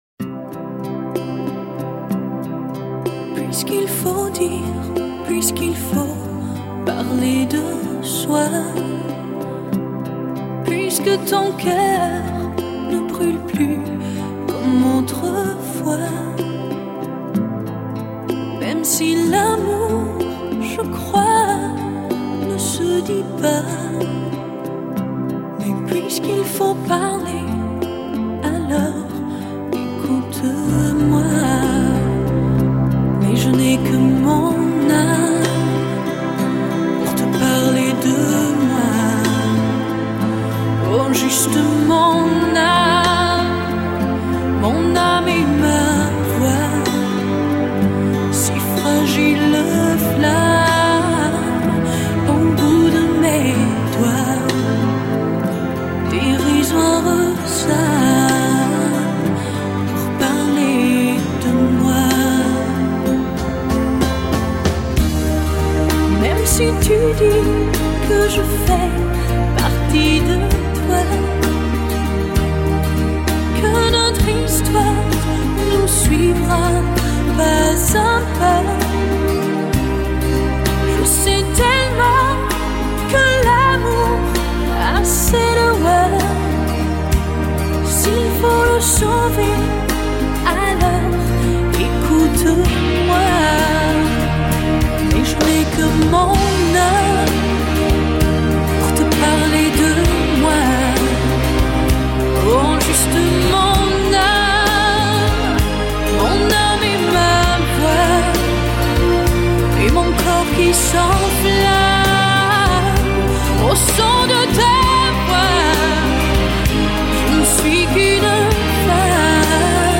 А музыка такая душевная и мечтательная...
Люблю французскую музыку.